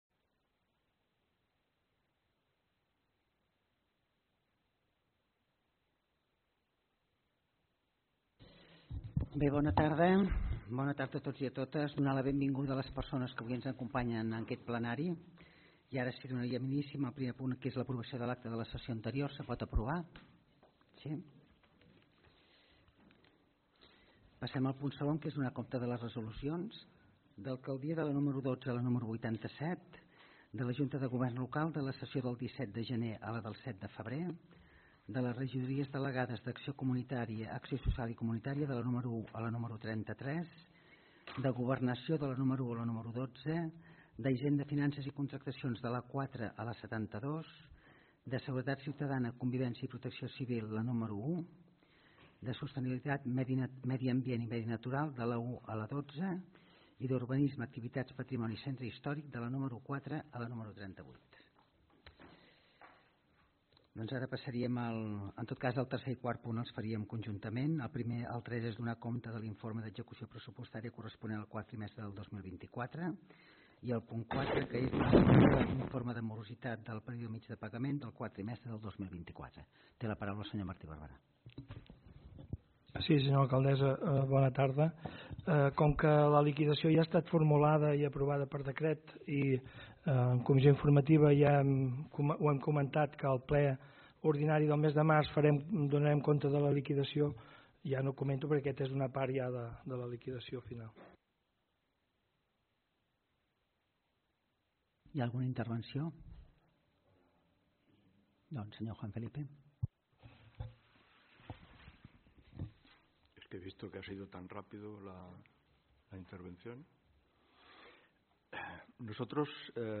Us oferim l’enregistrament de la sessió plenària ordinària de l’Ajuntament de Valls del dilluns 10 de març del 2025.